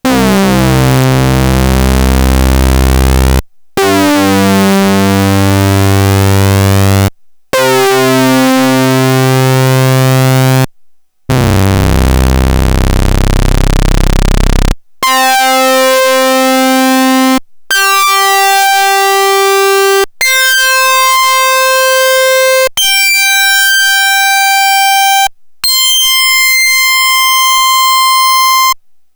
Il est suivi du Solaris qui a un rendu très flatteur je trouve (très large et très claquant, précis).
Aucune compression n'a été appliquée, c'est ce qui sort direct.
SolSawSync.wav